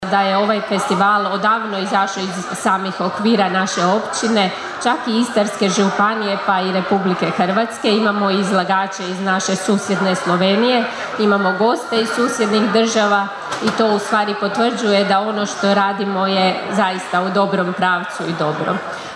Općinsku načelnicu Kršana Anu Vuksan posebno veseli: (